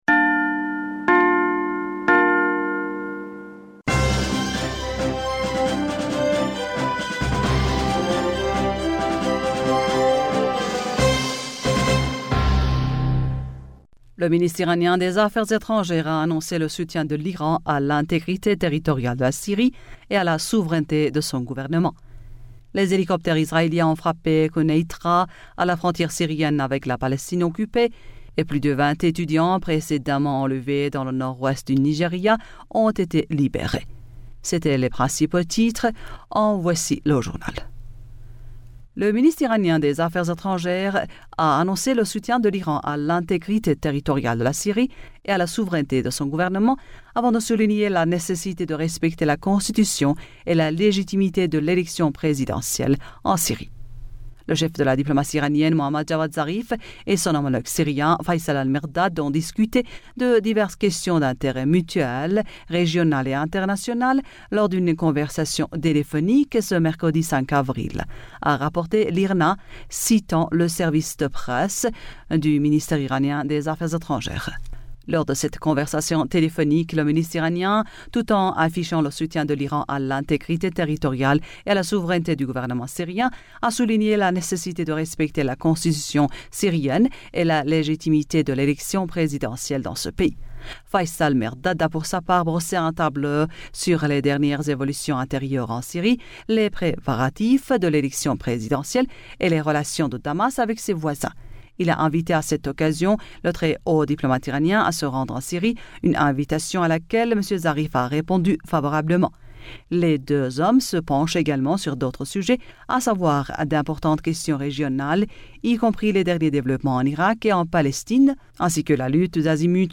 bulletin d'information